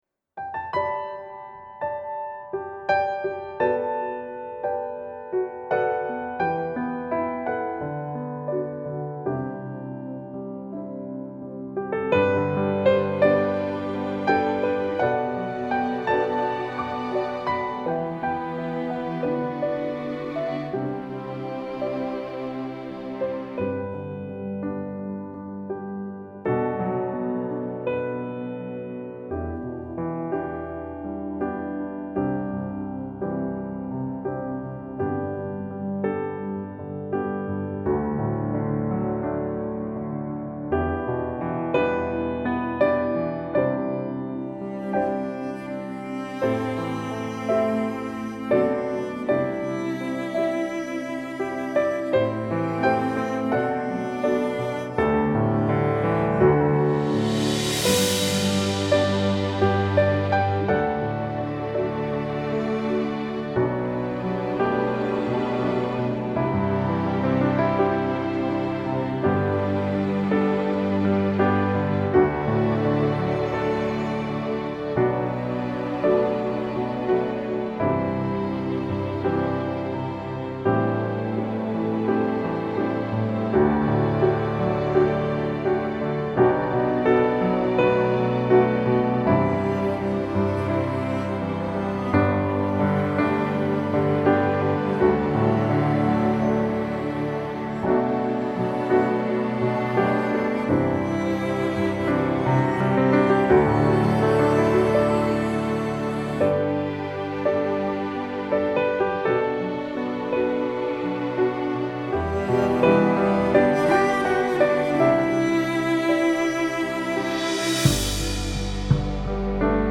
Vocal/Piano/Cello